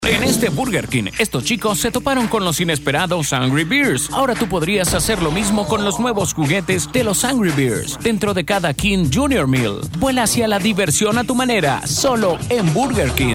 Voz comercial para su negocio o empresa con más de 18 años de experiencia dándole el tono y matiz a sus textos, para así transmitir el mensaje correcto a su futura cartera de clientes.
spanisch Südamerika
Sprechprobe: Sonstiges (Muttersprache):